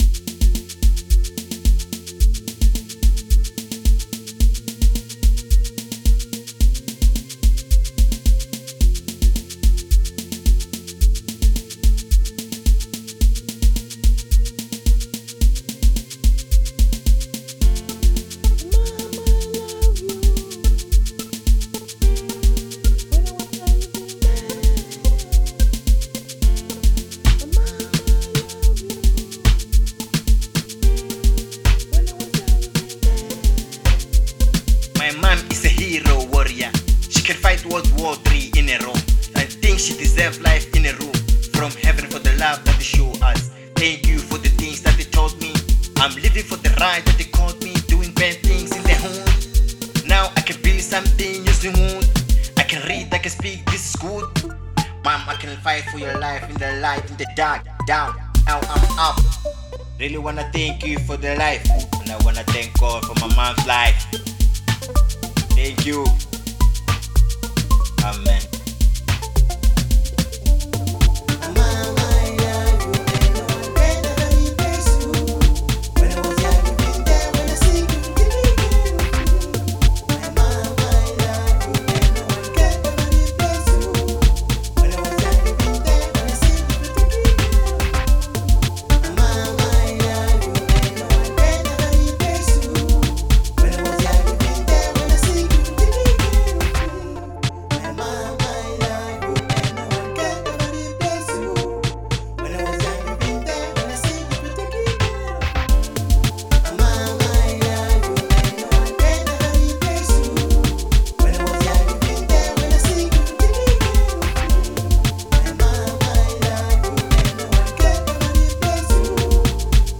03:17 Genre : Hip Hop Size